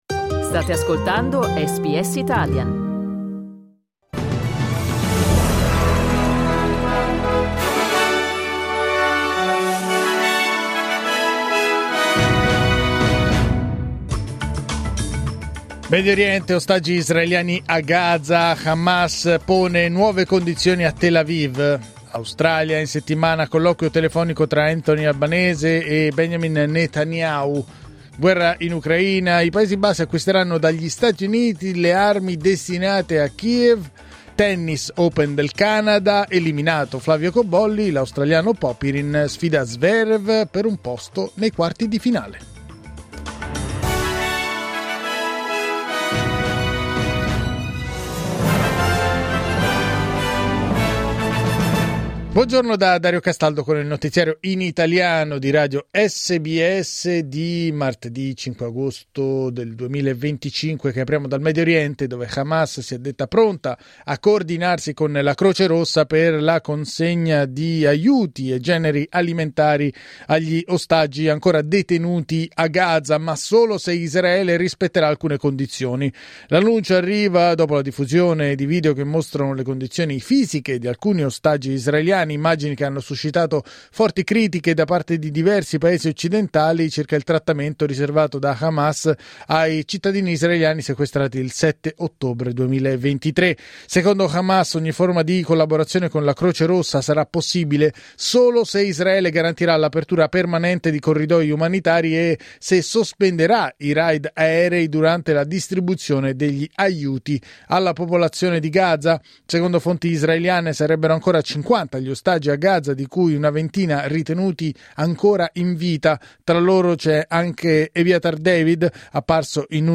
Giornale radio martedì 5 agosto 2025
Il notiziario di SBS in italiano.